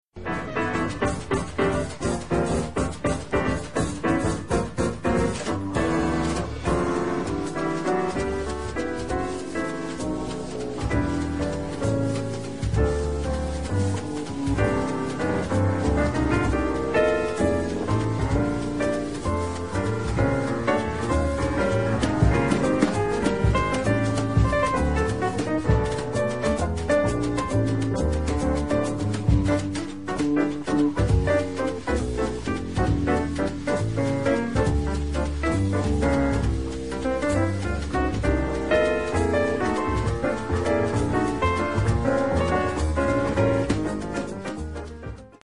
et puis cet autre au piano: